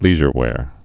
(lēzhər-wâr, lĕzhər-)